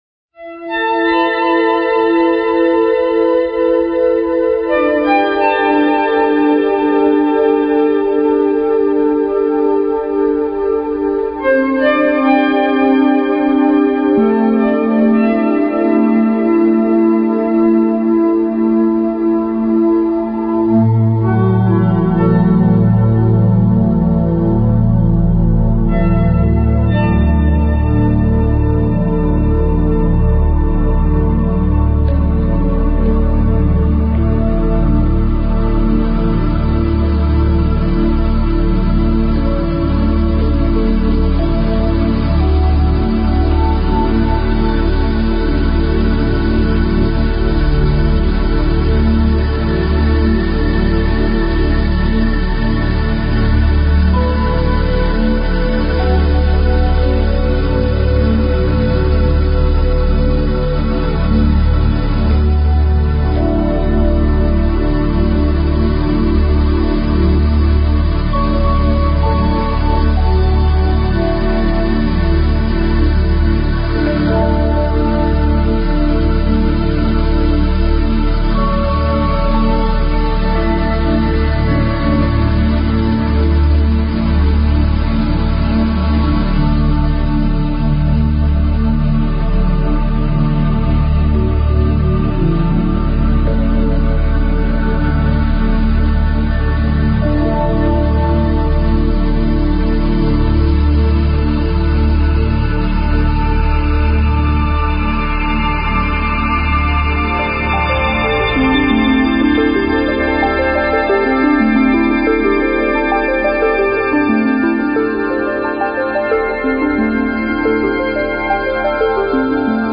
Talk Show Episode, Audio Podcast, Create_Abundance_Now and Courtesy of BBS Radio on , show guests , about , categorized as